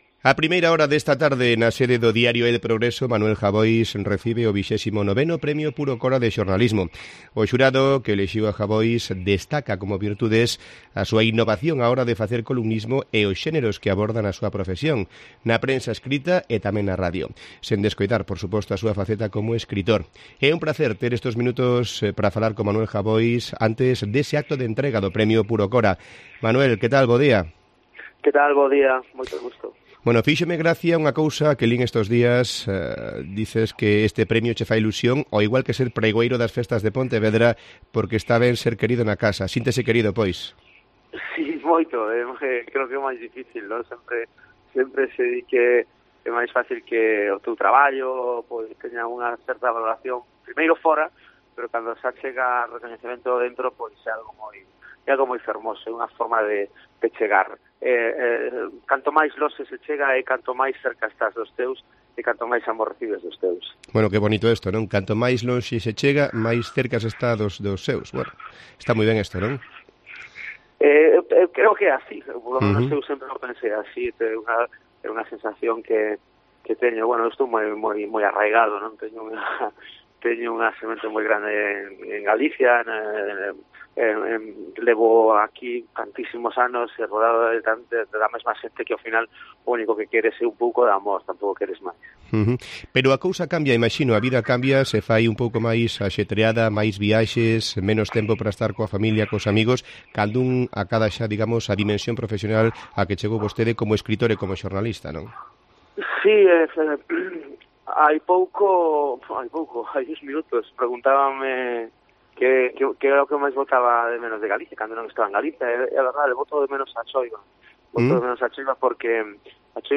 Entrevista a Manuel Jabois